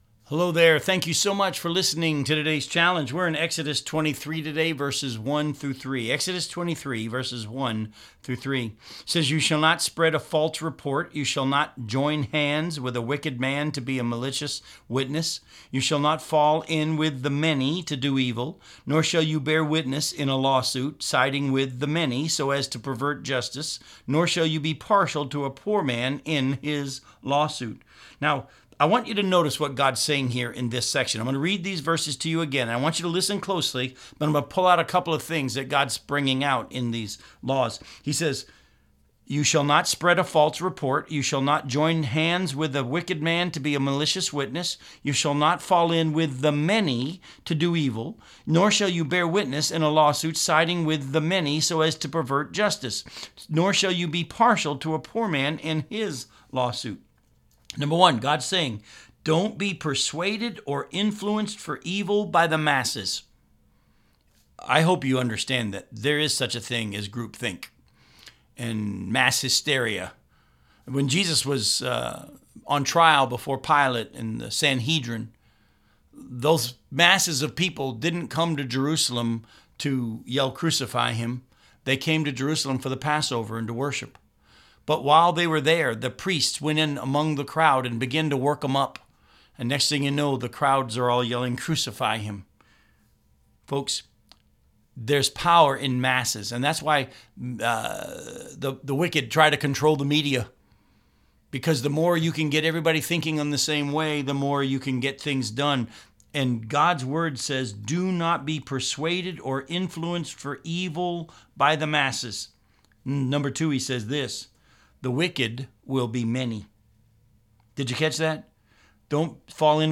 radio program aired on WCIF 106.3 FM in Melbourne, Florida